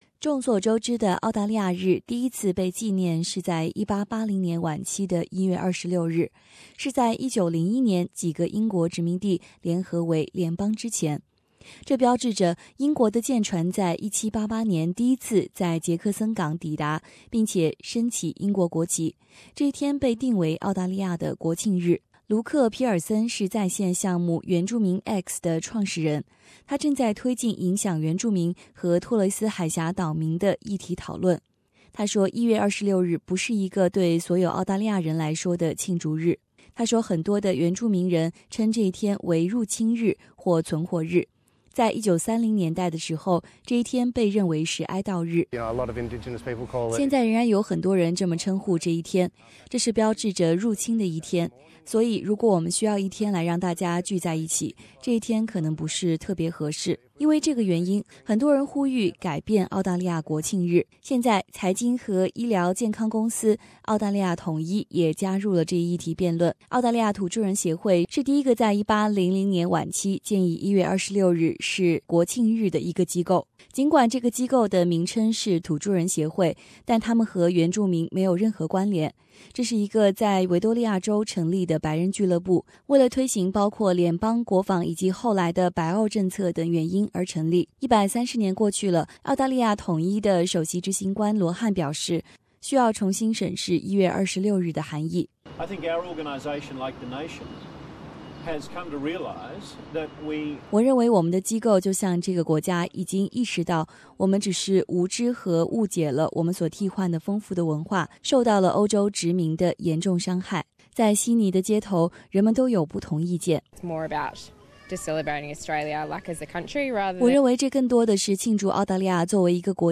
在悉尼的街头，人们都有不同的意见。